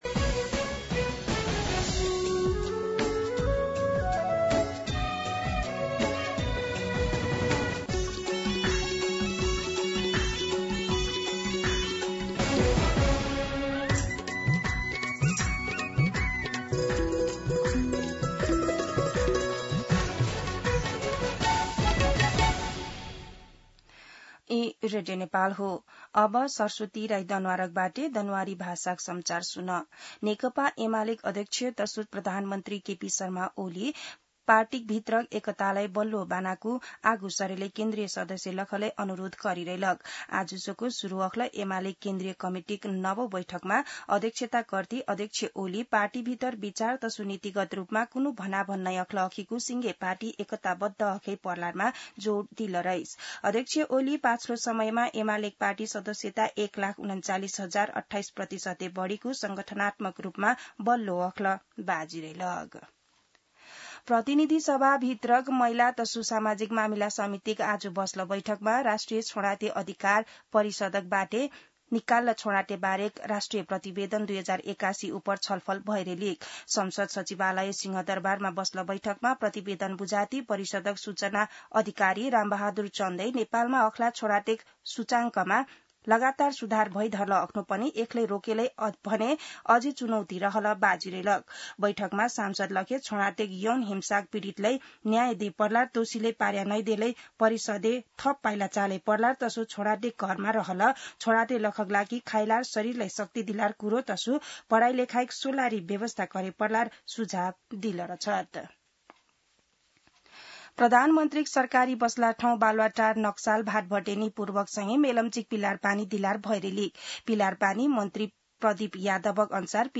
दनुवार भाषामा समाचार : ५ साउन , २०८२